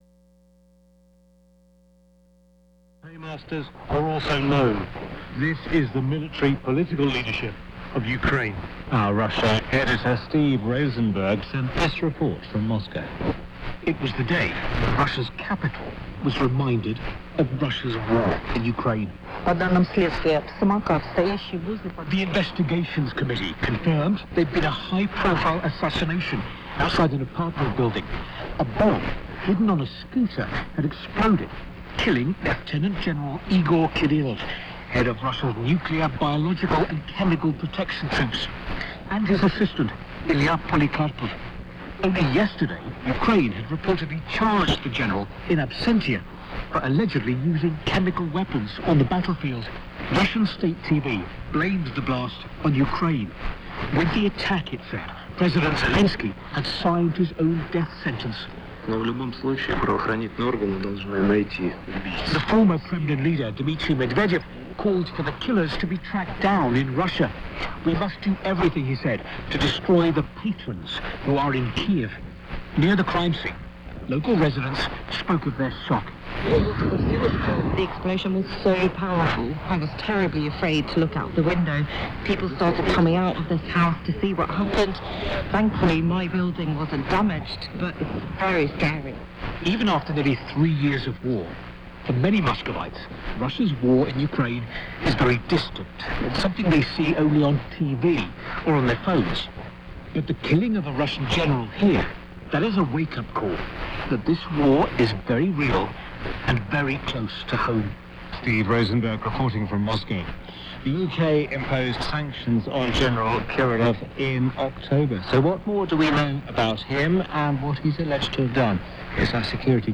Tinang, PHL transmitter. Good signal into EM80. 200' North facing Beverage. 59+40.
2219 UTC - English language news report (OM presenter) about Ukraine-Russia war, illegal whaling and other topics
2230 UTC - news continues (YL presenter)